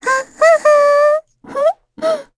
Lavril-Vox_Hum_kr.wav